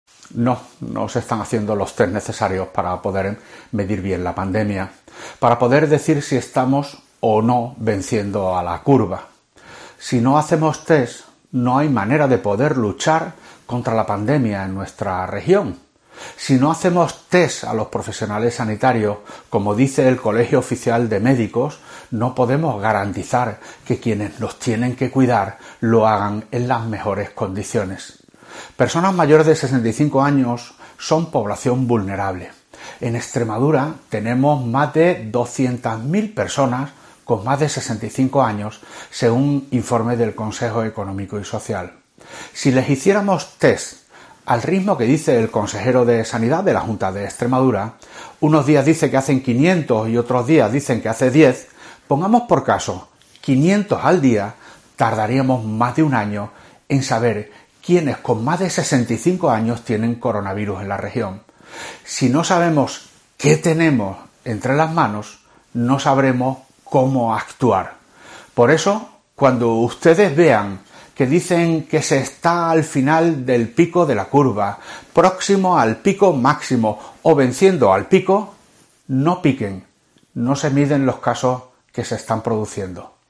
Monago, presidente del PP, en declaraciones sobre los test para el COVID-19 que se hacen en Extremadura